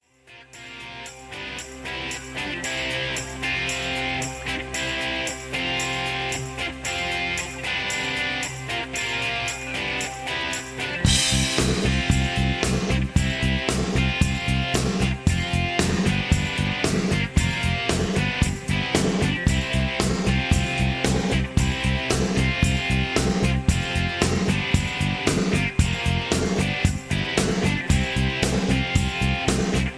(Version-2, Key-C) Karaoke MP3 Backing Tracks
Just Plain & Simply "GREAT MUSIC" (No Lyrics).